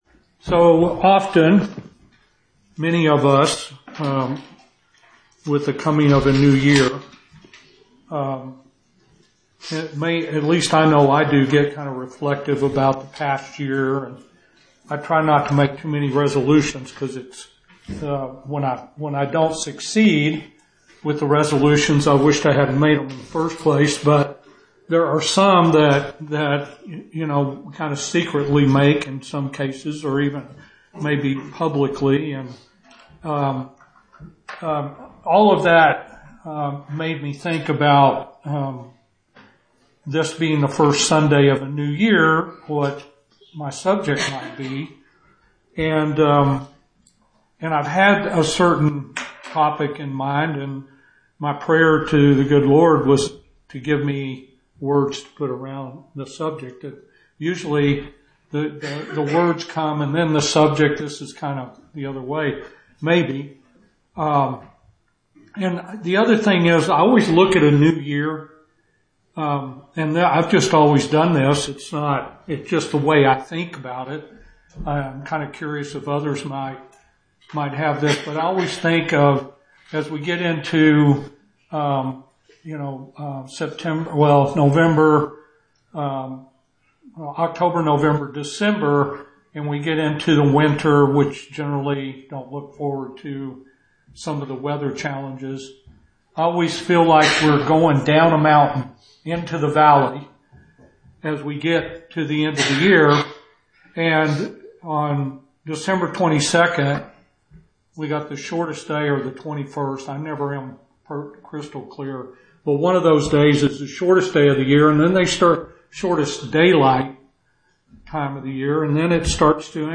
This sermon was recorded at Oxford Primitive Baptist Church Located in Oxford,Kansas